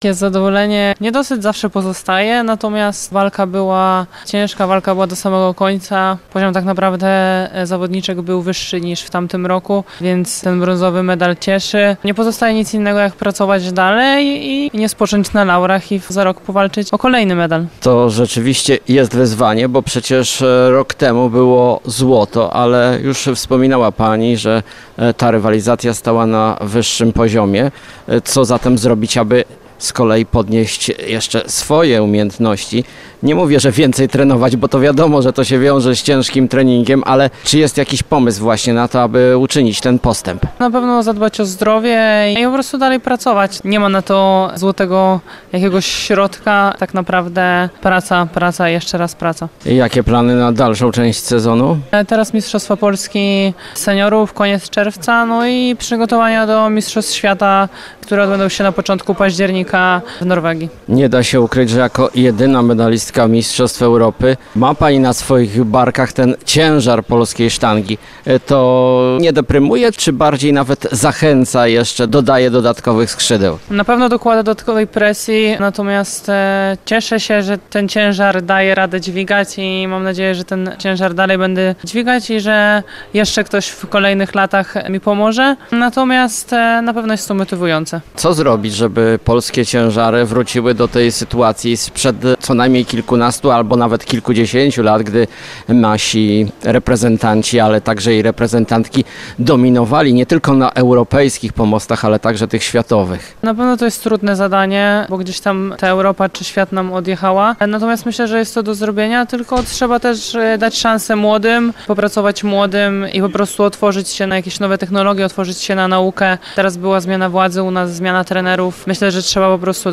Cała rozmowa w materiale dźwiękowym: